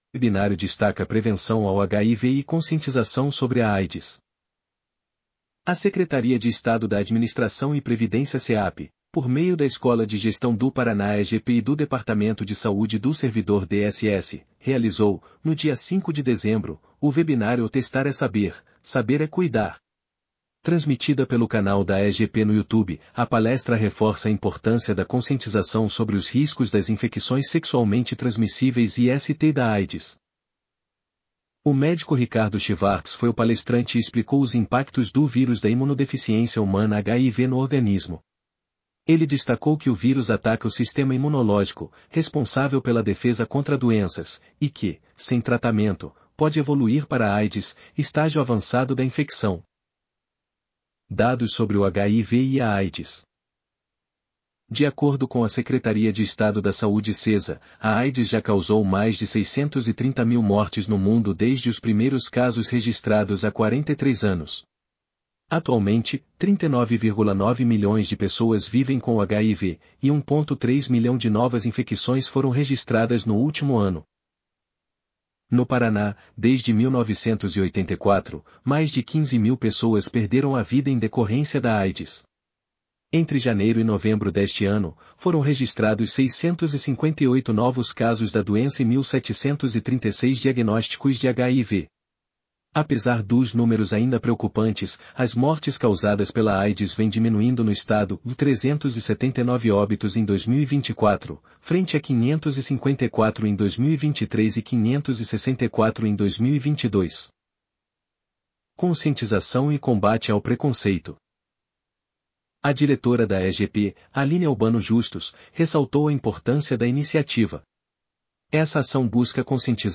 audionoticia_webinario_destaca_prevencao_ao_hiv.mp3